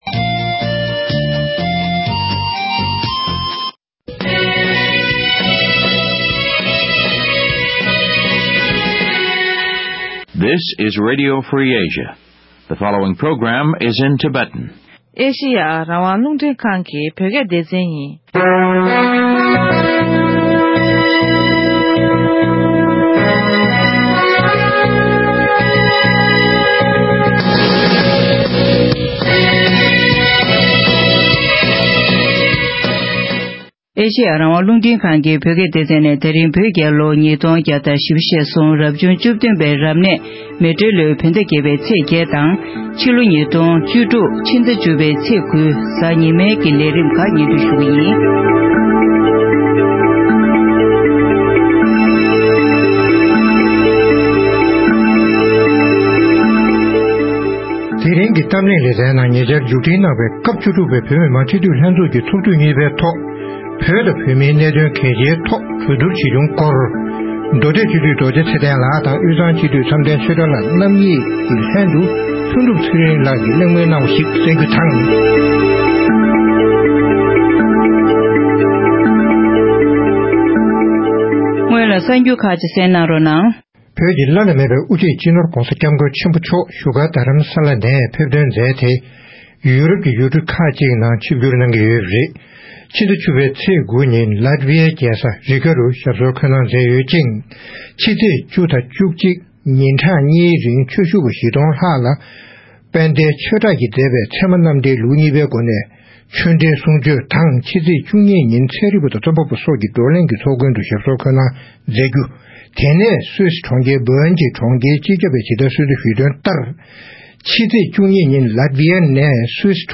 གླེང་མོལ་གནང་བ་ཞིག་གསན་རོགས